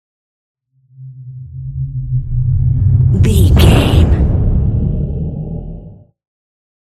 Deep whoosh to hit large
Sound Effects
dark
intense
woosh to hit